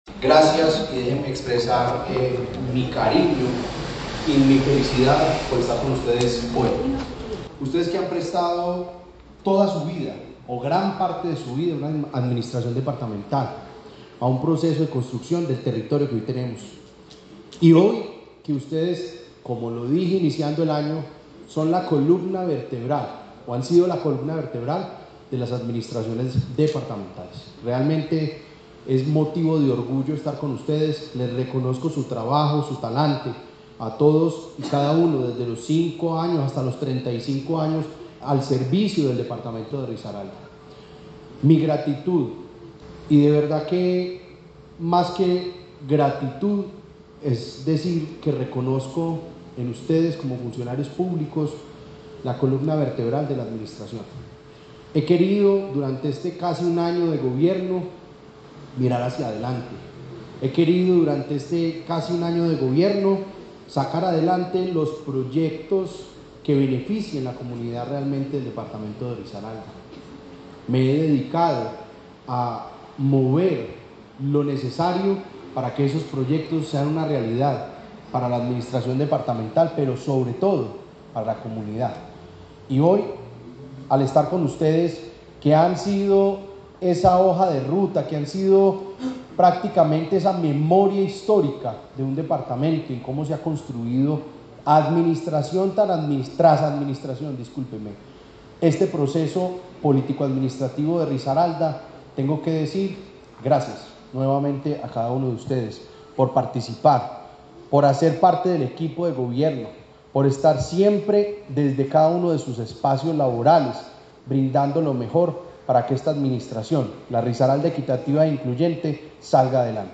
Para exaltar los logros y méritos de quienes se destacan por su trayectoria en administración departamental, la Gobernación de Risaralda lideró el evento ‘Tiempos de Servicios’.